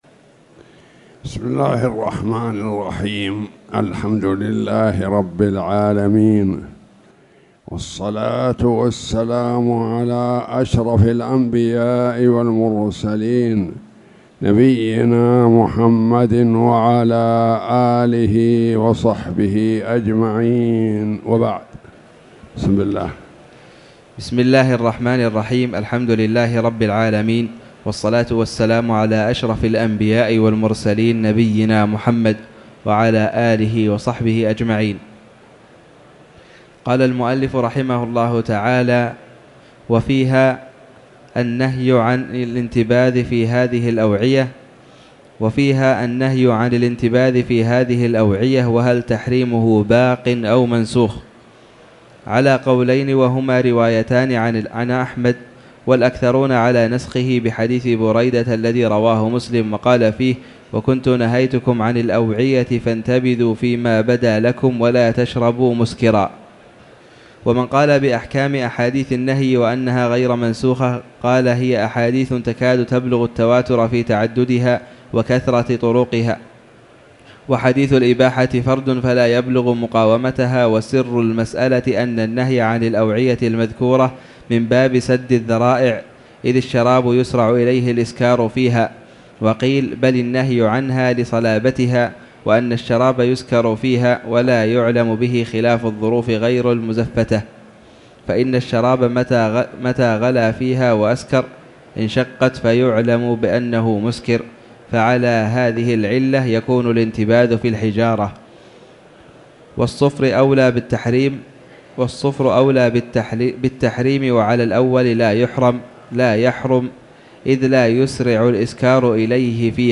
تاريخ النشر ٤ جمادى الآخرة ١٤٣٨ هـ المكان: المسجد الحرام الشيخ